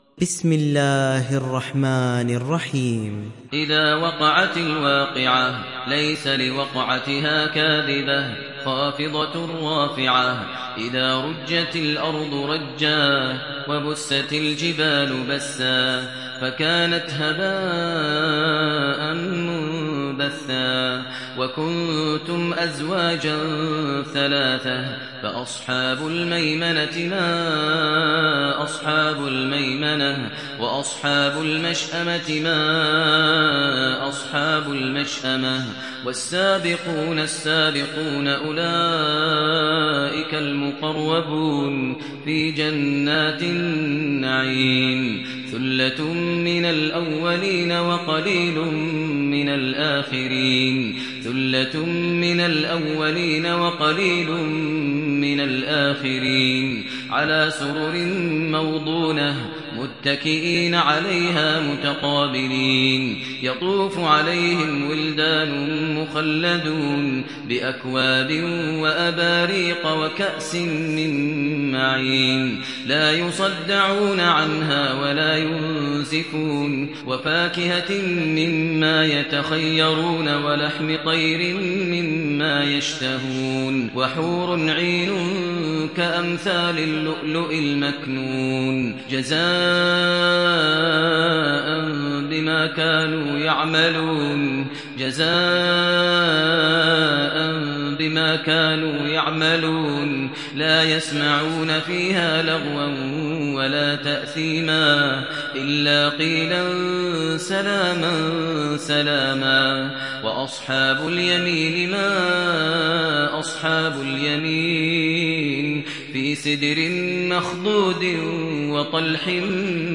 Vakia Suresi İndir mp3 Maher Al Muaiqly Riwayat Hafs an Asim, Kurani indirin ve mp3 tam doğrudan bağlantılar dinle